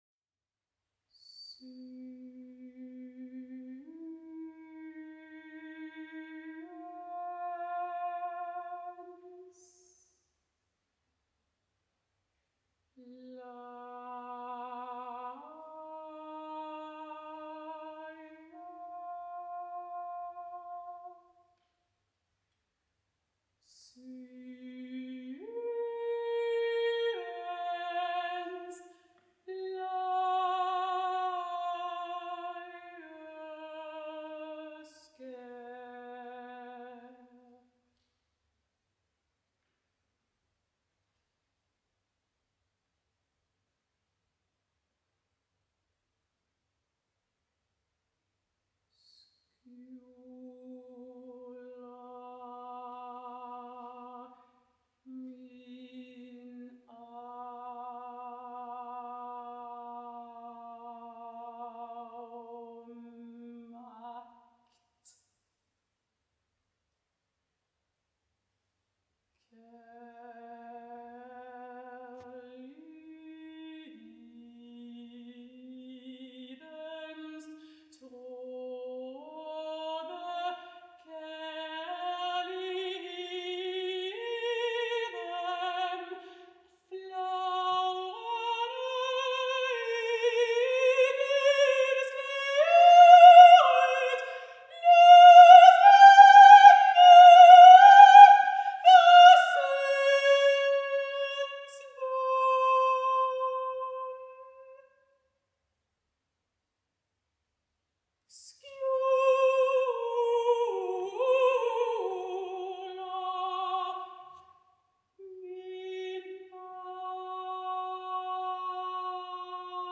Genre vocal music
Instrumentation mezzo soprano solo
place Black Diamond, Royal Library, Copenhagen
Recording type live
Musical styles and elements melodic, modal